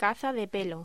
Locución: Caza de pelo